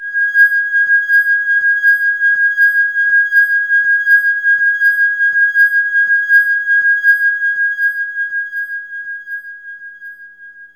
Index of /90_sSampleCDs/E-MU Producer Series Vol. 3 – Hollywood Sound Effects/Human & Animal/Wine Glasses
WINE GLAS01R.wav